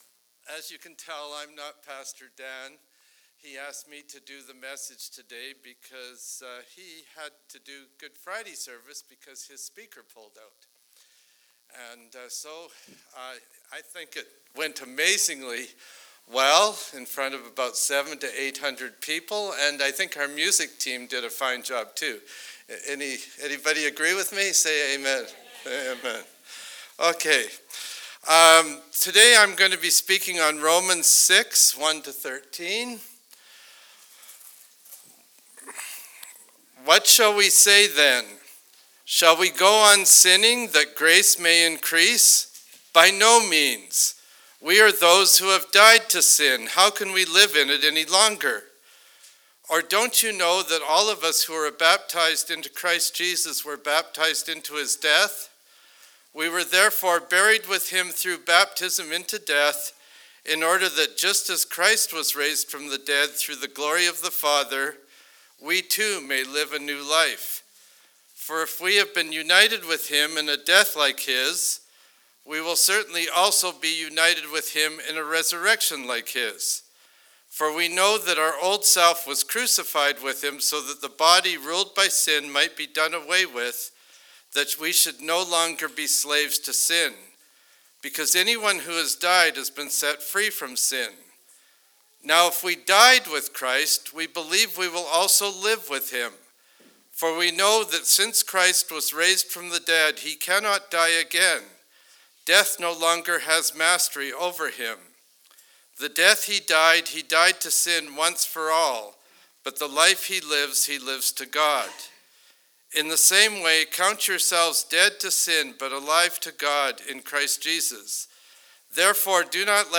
April 5 Sermon